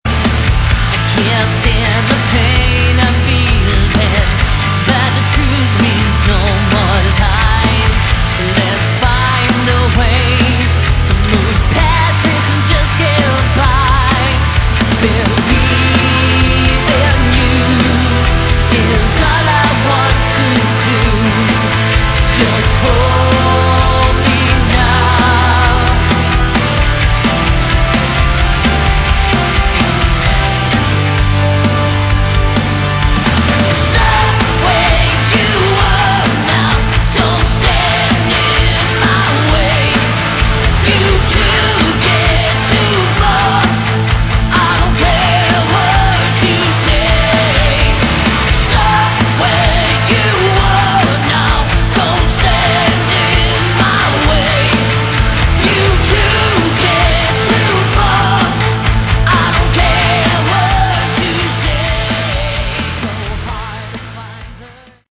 Category: Hard Rock
lead vocals, keyboards
rhythm guitar
Lead guitar
bass, backing vocals
drums